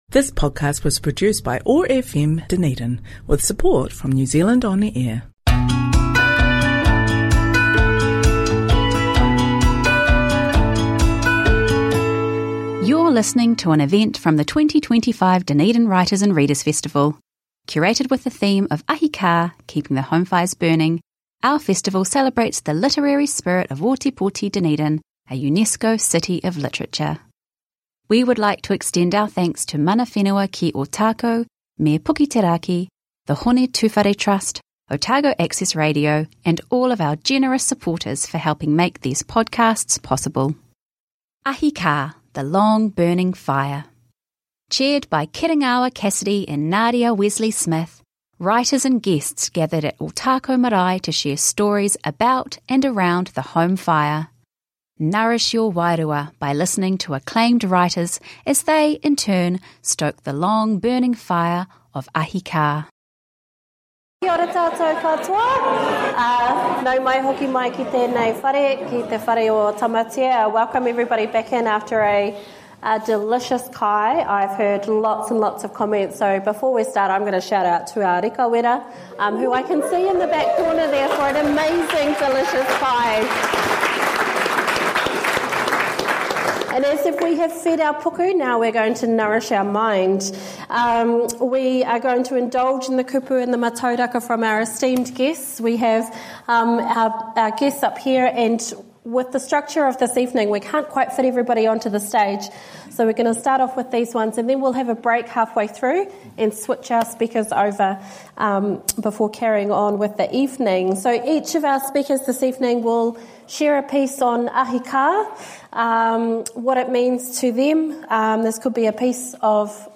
We gather at Ōtākou Marae to share stories about and around the home fire.